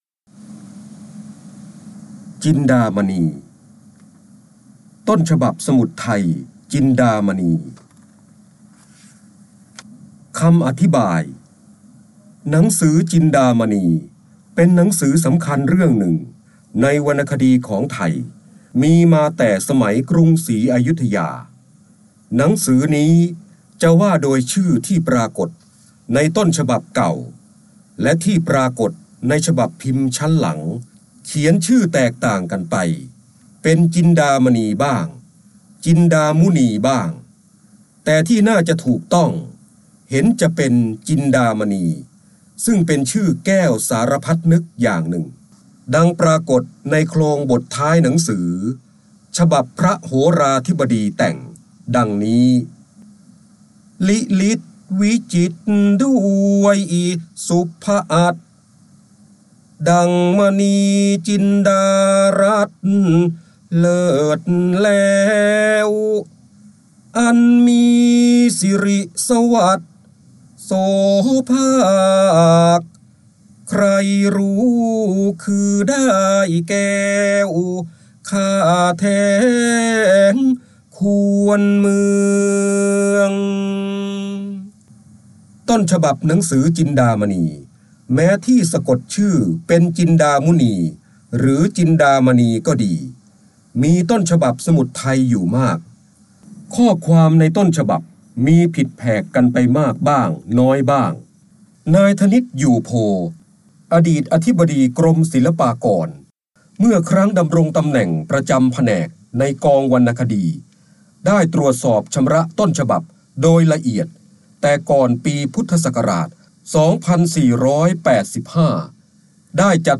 131 116 ตัวอย่าง ดาวน์โหลด ส่ง eCard เสียงบรรยายจากหนังสือ จินดามณี คำอธิบายหนังสือ ได้รับใบอนุญาตภายใต้ ให้เผยแพร่-โดยต้องระบุที่มาแต่ห้ามดัดแปลงและห้ามใช้เพื่อการค้า 3.0 Thailand .